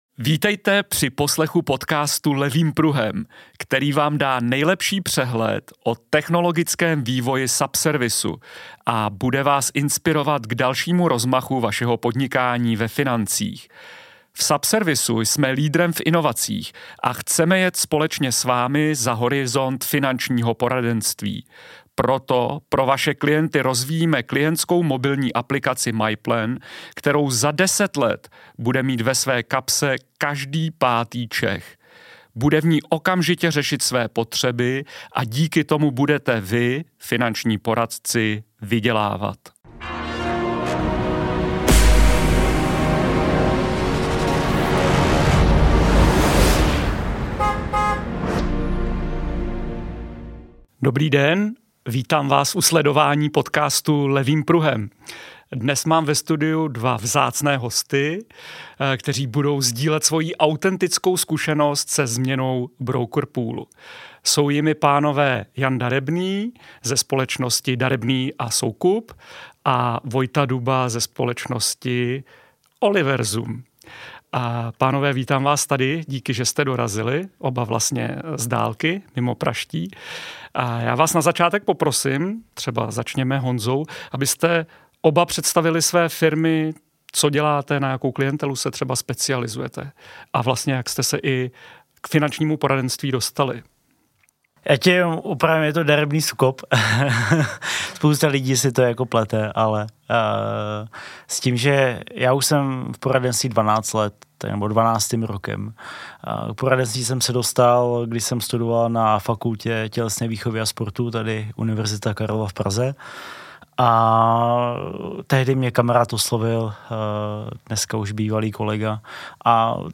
Každý lídr, který je nespokojený se svojí servisní firmou, stojí před těžkou volbou, zda to ještě nějakou dobu vydržet, nebo znovu podstoupit celý proces přestupu. Přinášíme inspirativní rozhovor se dvěma lídry, kteří takovou výzvu zvládli.